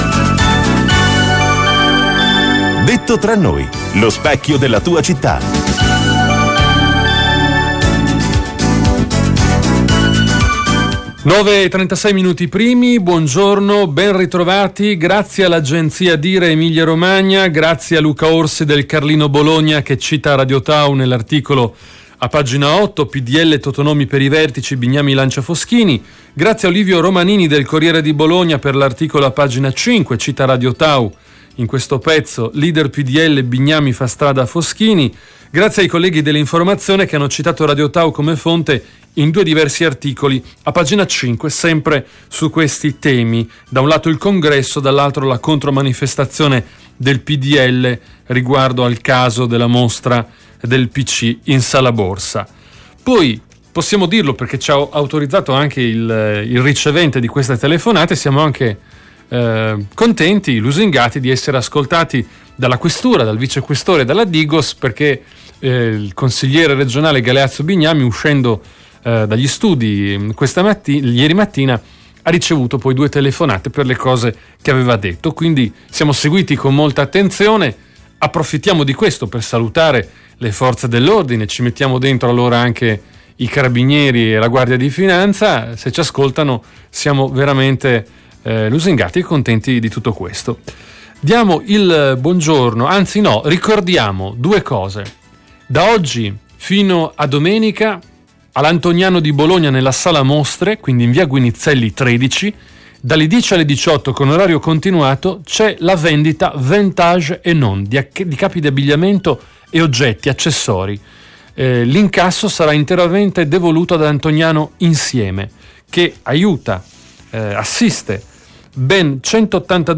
Intervista al capogruppo PD Sergio Lo Giudice a RadioTau